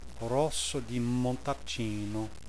Italian Wine Pronunciation Guide
Click on a speaker symbol to hear the word spoken aloud.